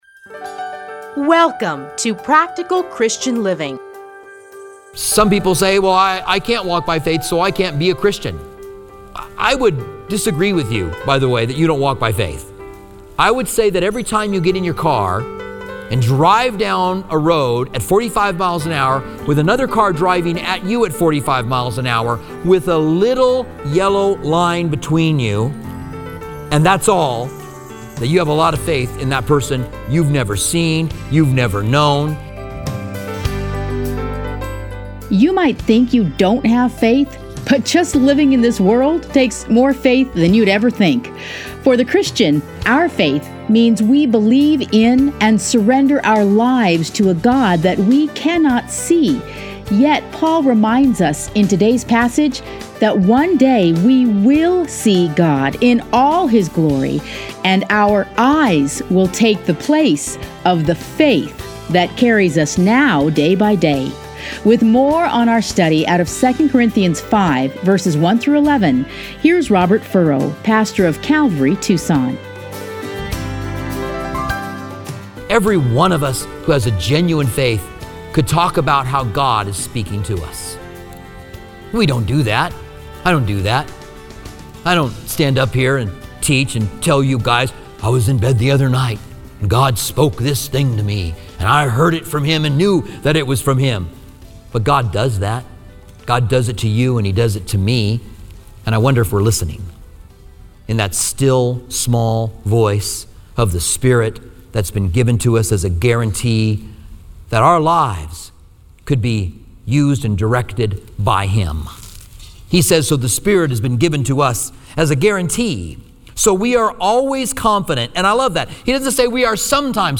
Listen here to a teaching from 2 Corinthians.